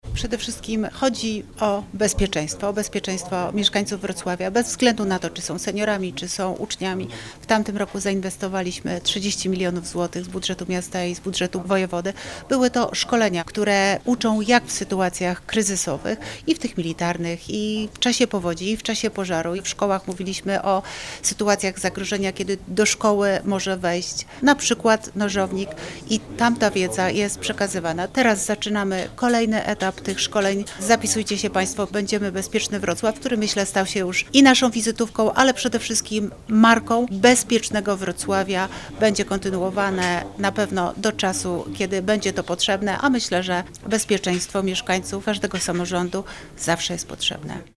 Dodatkowo ponad 2 tys. mieszkańców skorzystało z warsztatów w specjalnej strefie edukacyjnej, gdzie można było przećwiczyć zdobyte umiejętności – podkreśla Renata Granowska, wiceprezydent Wrocławia.